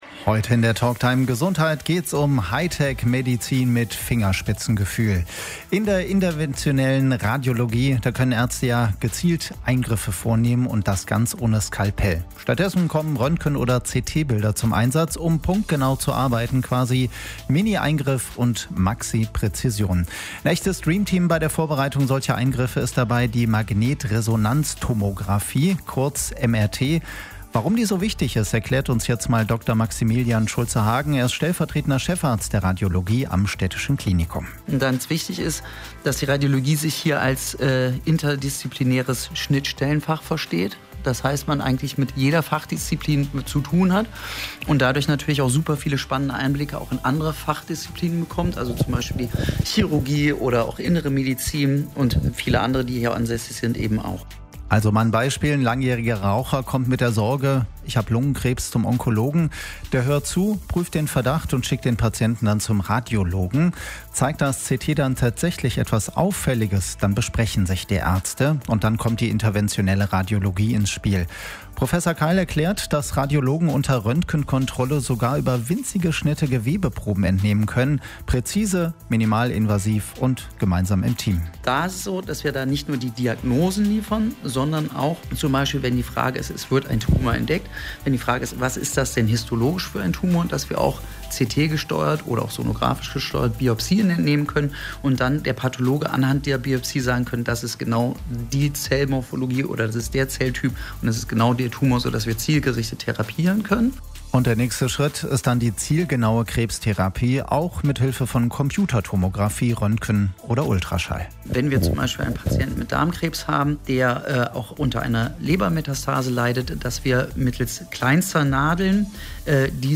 Die Radiosprechstunde lief am Samstag, 25. Juli 2025, von 12 bis 13 Uhr bei Radio RSG und kann hier nachgehört werden.